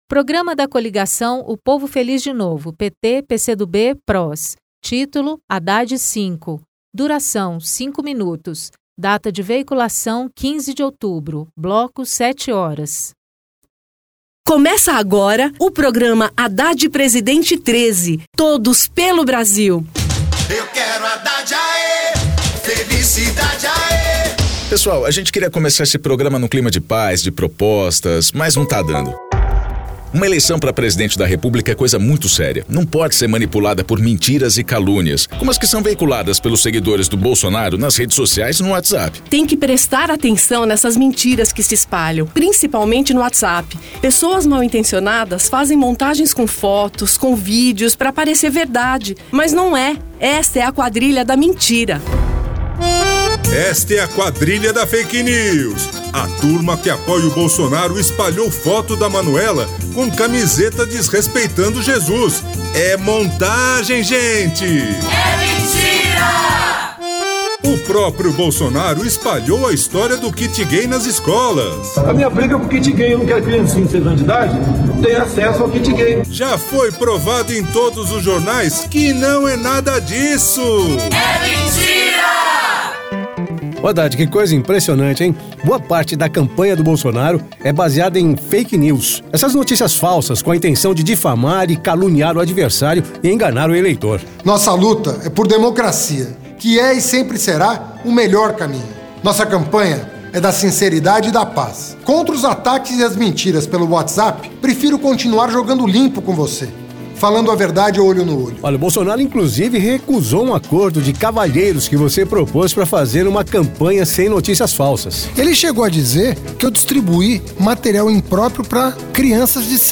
Gênero documentaldocumento sonoro
Entrevista : temático